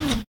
whiz_01.ogg